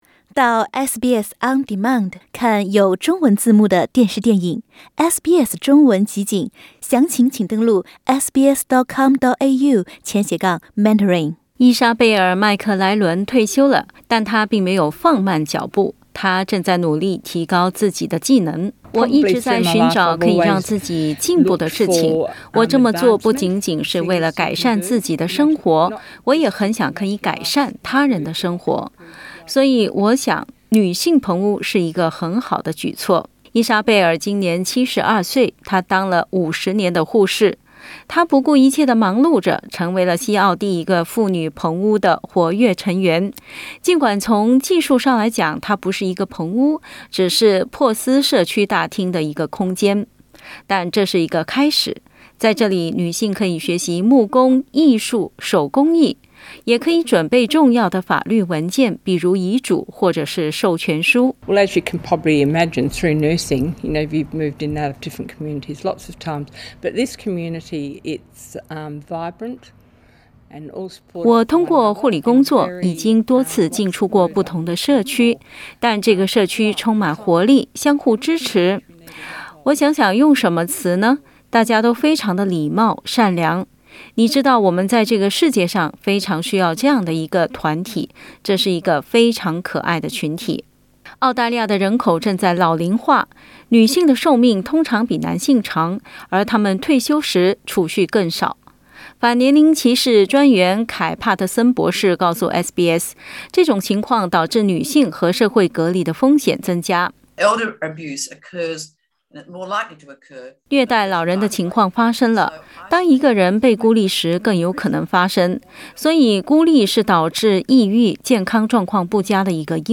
澳大利亚老年女性面临越来越大的被孤立的风险，许多人正在加入女性棚屋（women’s sheds），在那里学习新技能并结交新朋友。（点击图片收听报道）